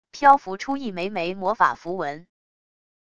漂浮出一枚枚魔法符文wav音频